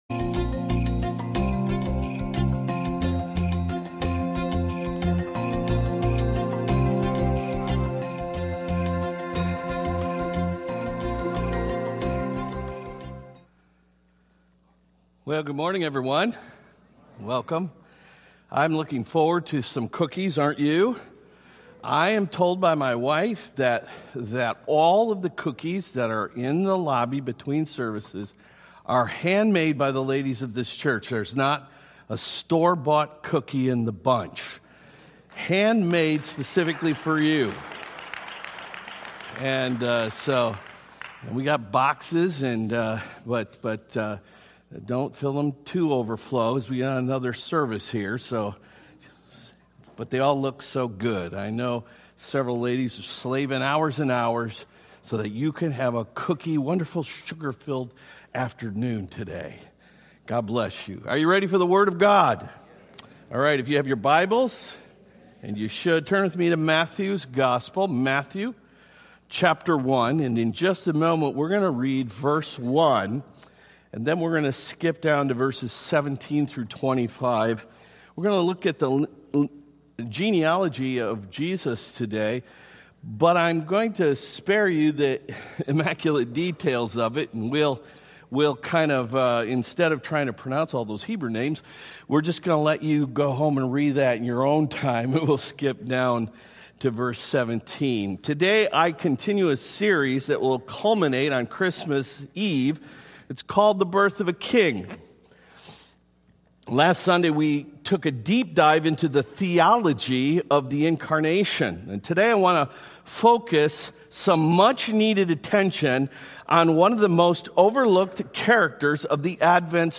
Sermons & Media – Tiffany Fellowship Church | Kansas City